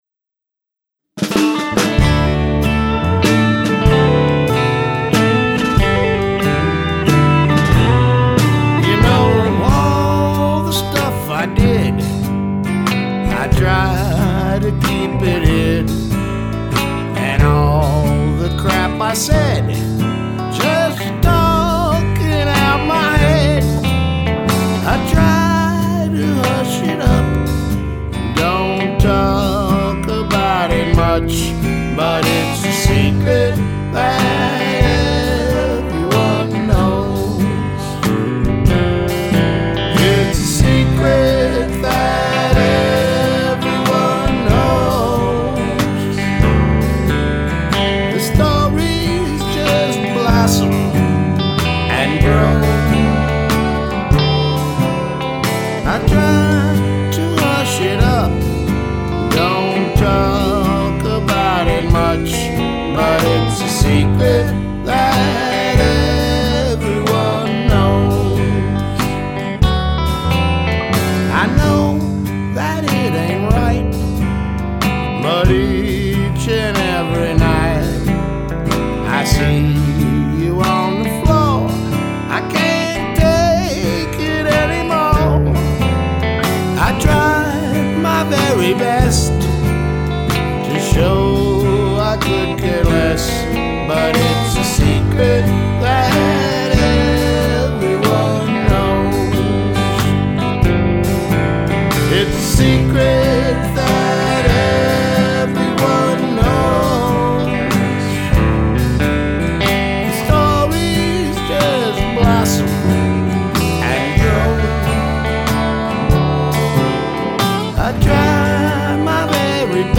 Howsa bout a country tune?
in the cave
bass
yours truly on drums, vox and authorshit...er I mean ship...sorry:o